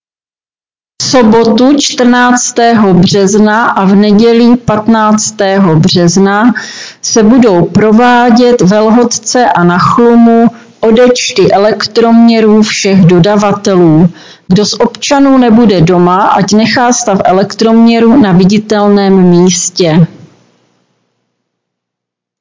Hlášení místního rozhlasu
Hlášení ze dne 13.3.2026